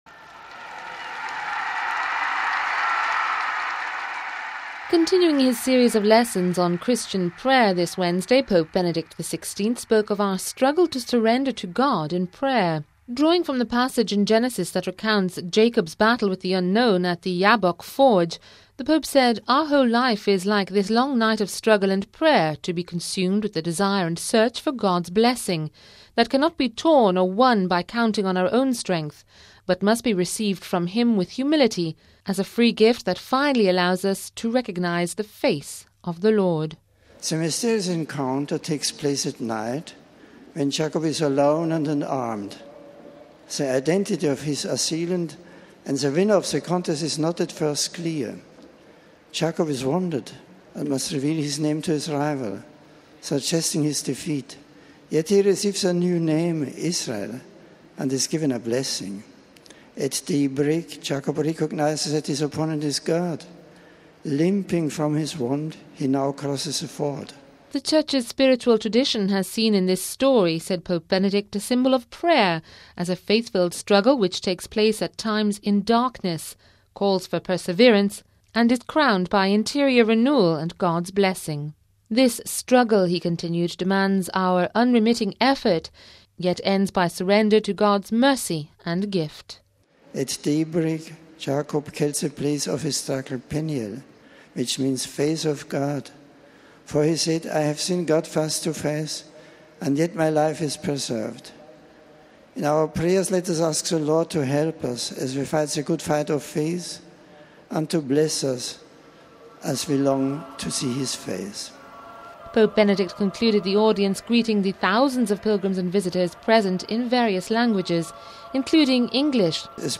Pope Benedict concluded the Audience greeting the thousands of pilgrims and visitors present in various languages,